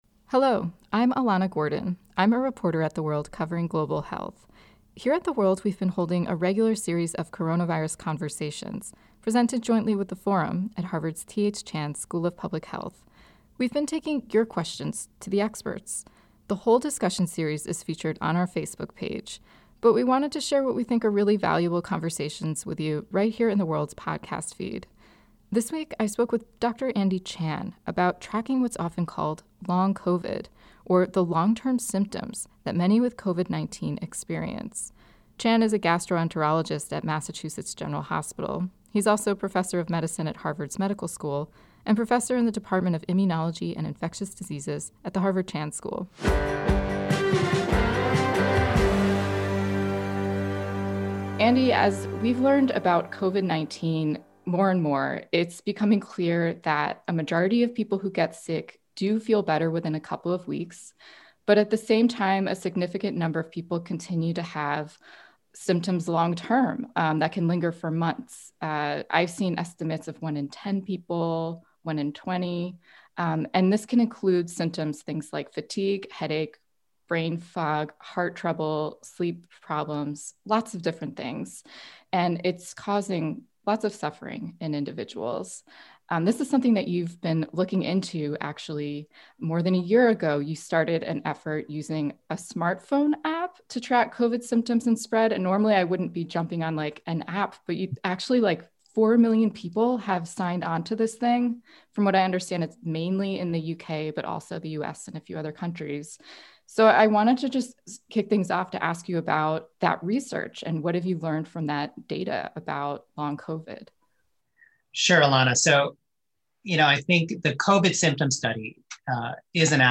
moderated a discussion